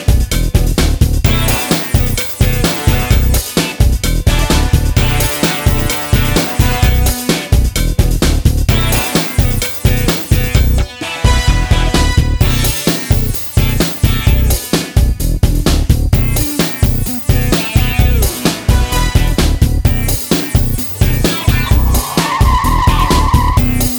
no Backing Vocals Rock 3:13 Buy £1.50